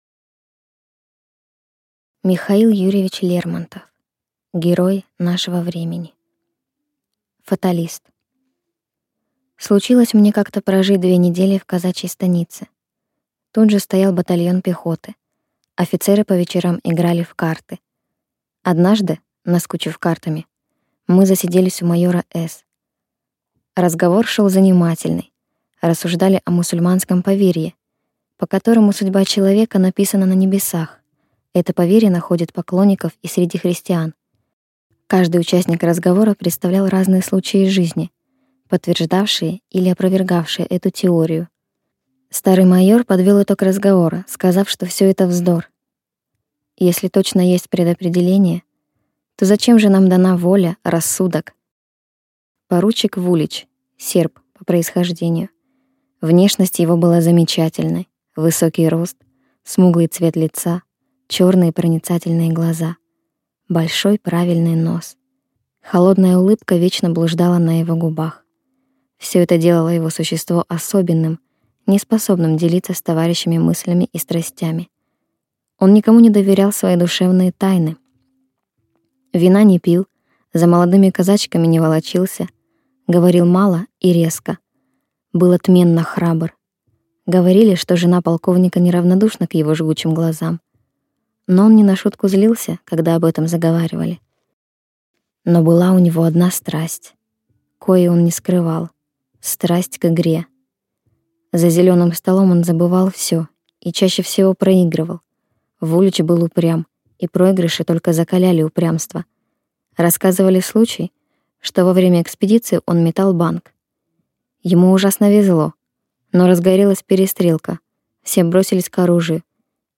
Краткое аудио содержание "Фаталист" М.Ю. Лермонтова
Здесь Вы можете прослушать краткий пересказ книги М.Ю. Лермонтова - Фаталист в аудио онлайн.
М.Ю.Лермонтов-Фаталист-Среднее-качество.mp3